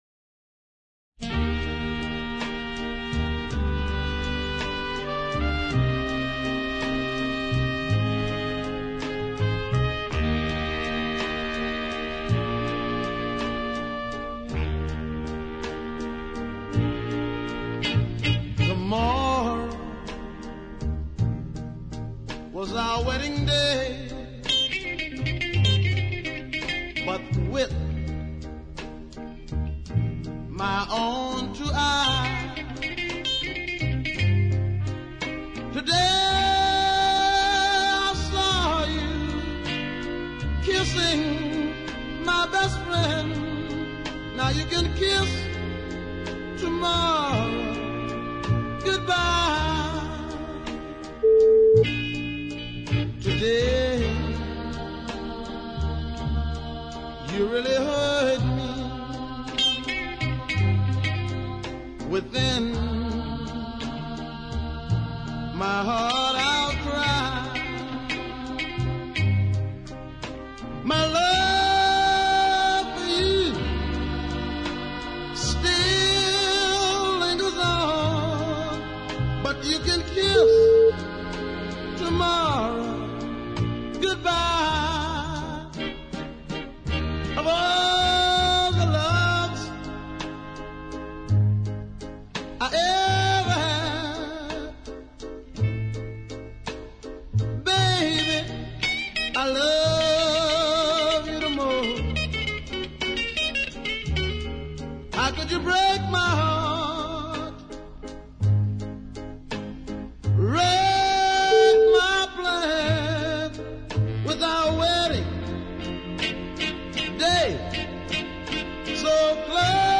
a lovely blues ballad